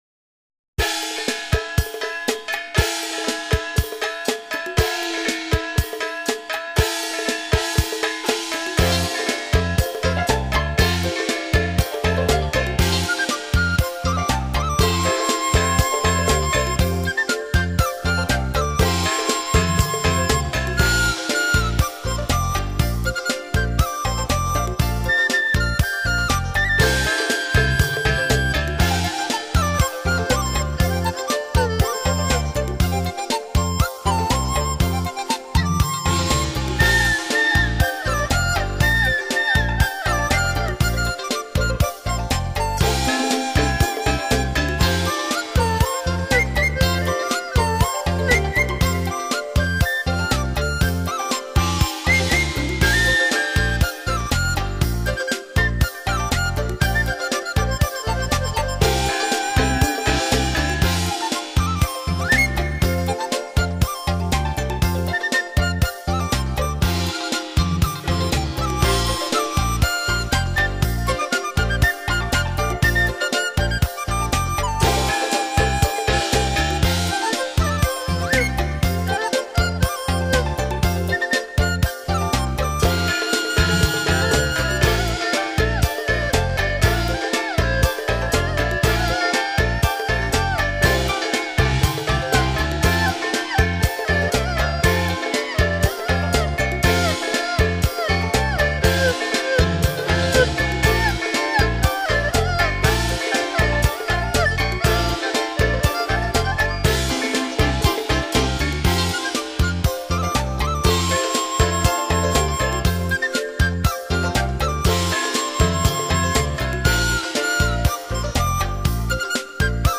有什麼樣的樂器，能像胡琴、把烏、笙、笛、鑼、鈸和唐鼓無間的合作，盡興地製造出喜洋洋的氛圍？